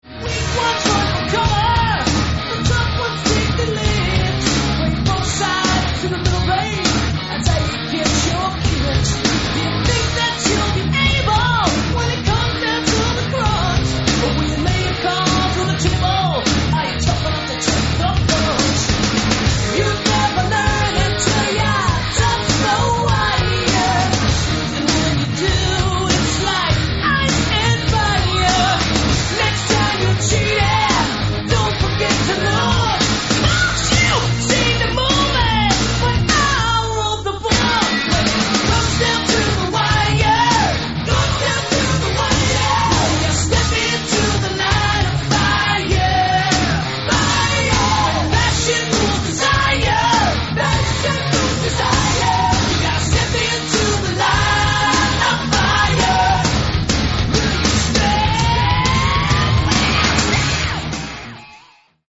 Sounds like: Tone Deaf Leppard